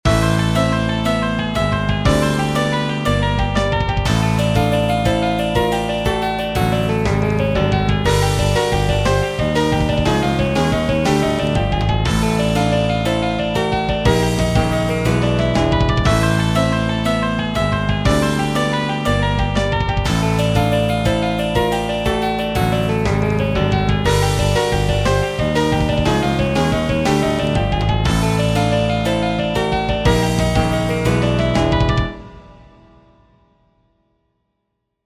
Short 120bpm loop in 17edo, retuned to 19edo
17edo_demo_but_in_19edo.mp3